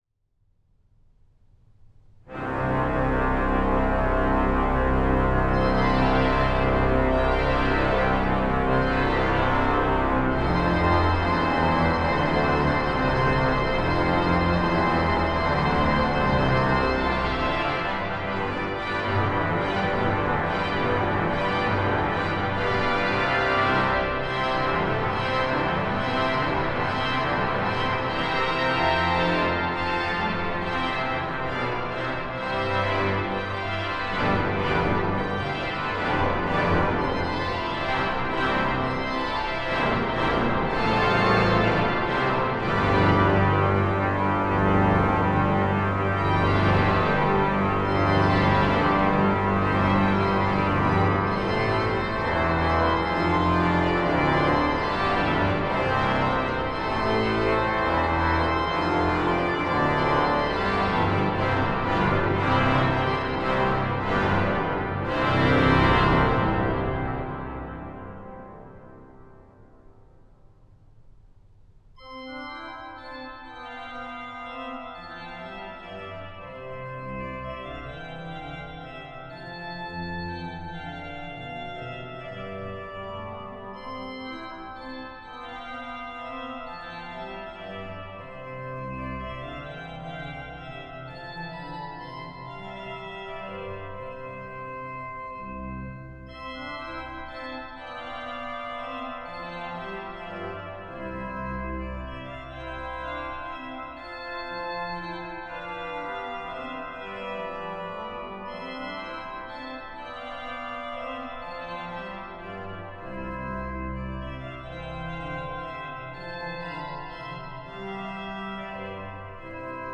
C. Balbastre – Tapage – Kathedrale Basiliek St. Bavo Haarlem
24-bit opname gemaakt met Sound Devices MixPre-6, Neumann KM83i, VDH Integration.